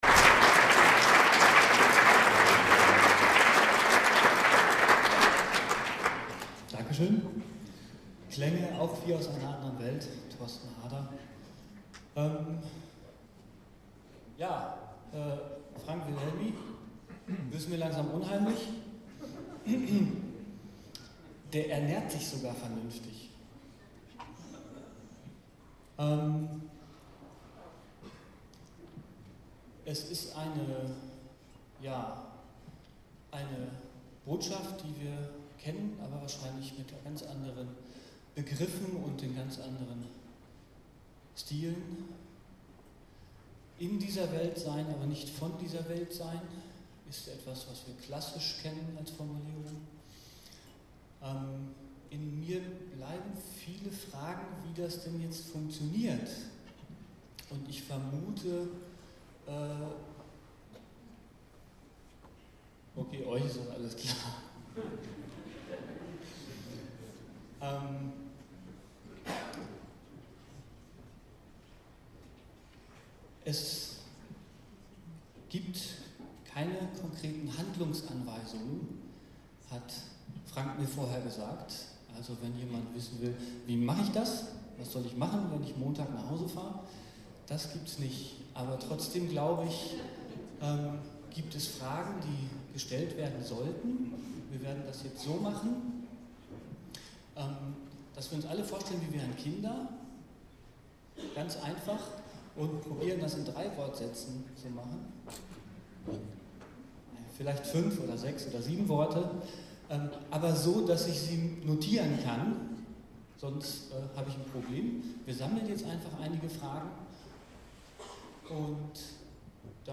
tagung2010_nachfragen2.mp3